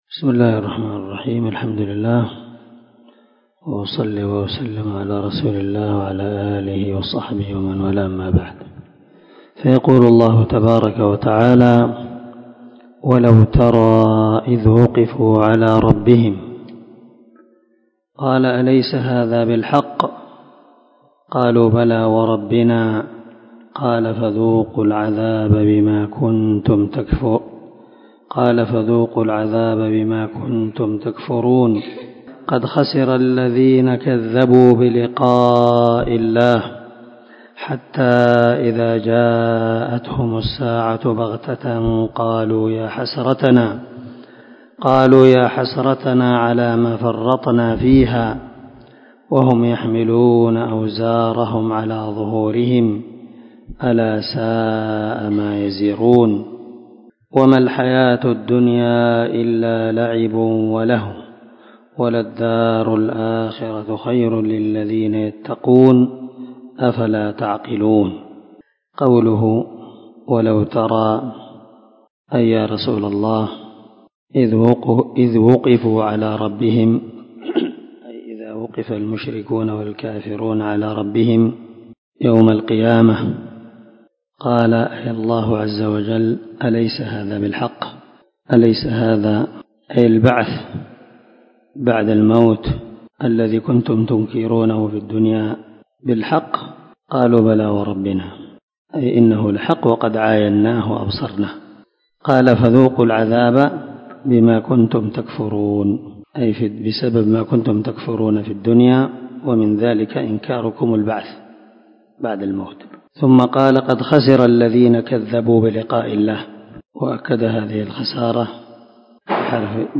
400الدرس 8 تفسير آية ( 30 – 35 ) من سورة الأنعام من تفسير القران الكريم مع قراءة لتفسير السعدي